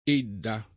Edda [$dda] pers. f. — diffusa in Tosc., anche nell’area fior., una pn.